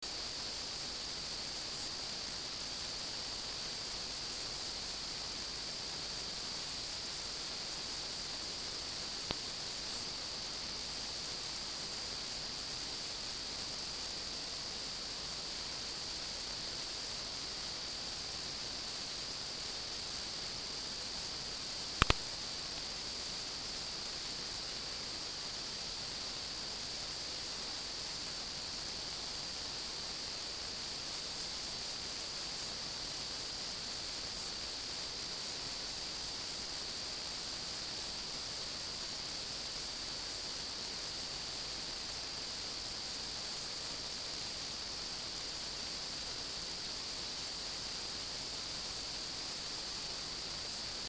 20 sept Michael Billie Jean on tube amplifier detail
This is an experiment with a tube amplifier.
Input Music and see what the ultrasound microphone will pick up near the cable.  It picked up the music.
20-sept-Michael-Billie-Jean-on-tube-amplifier-detail.mp3